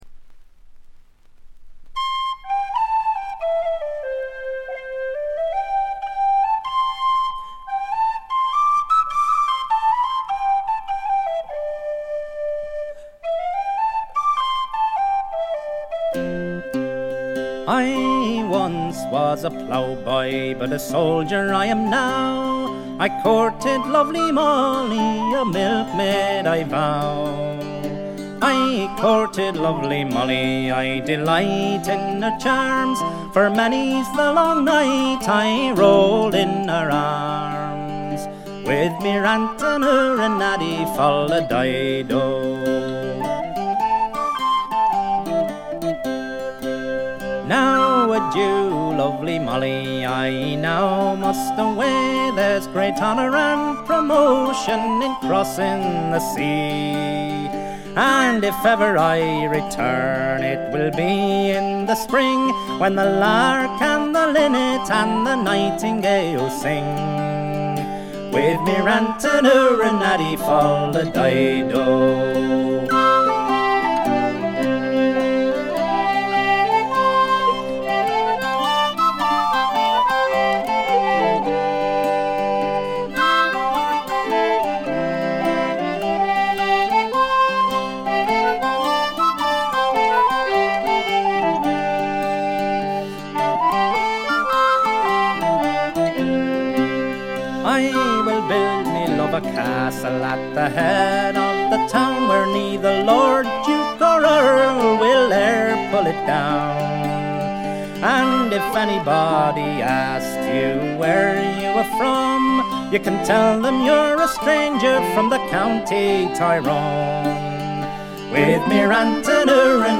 ほとんどノイズ感無し。
声自体が重層的で深いんですよね。
試聴曲は現品からの取り込み音源です。
Fiddle
Bouzouki
Concertina